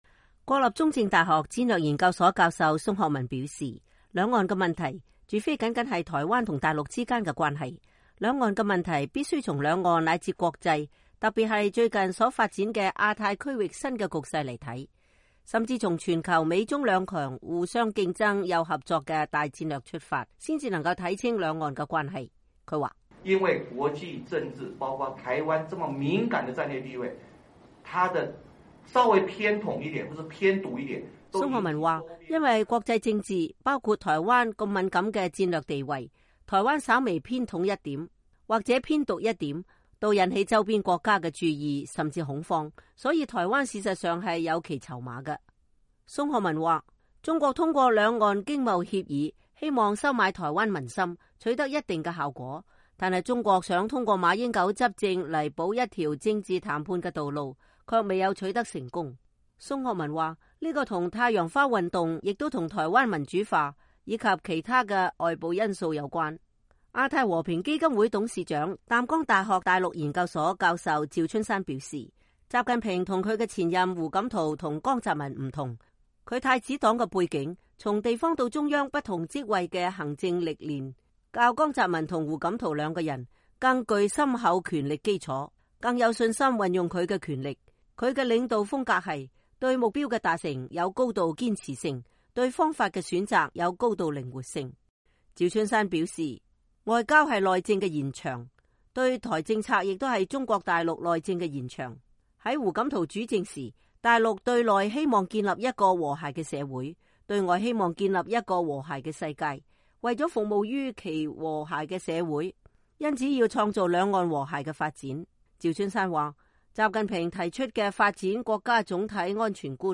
這些學者是在美國約翰霍普金斯大學高等國際研究院中國研究系主任藍普頓教授(David Lampton)的中文譯本《從鄧小平到習近平》新書發佈及座談會上發表以上評論的。